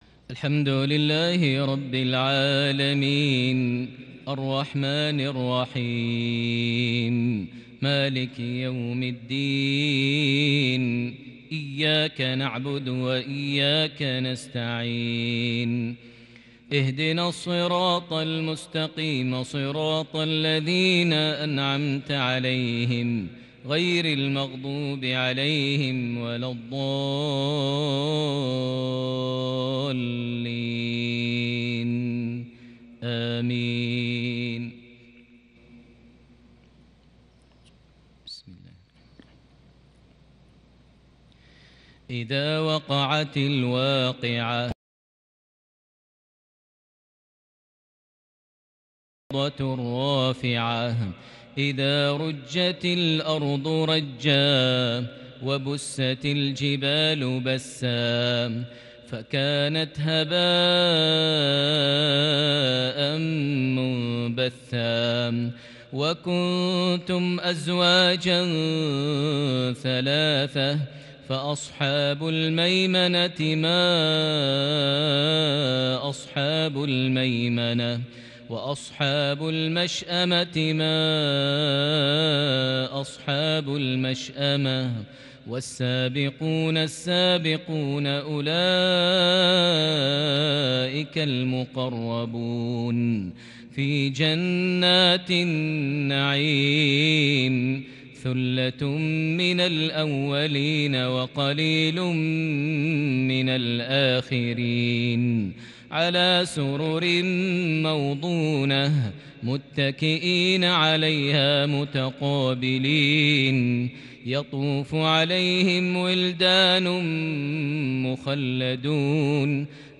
المعيقلي جمع ما بين الأبداع والتميز في عشائية فريدة من سورة الواقعة (1-40) | 23 صفر 1442هـ > 1442 هـ > الفروض - تلاوات ماهر المعيقلي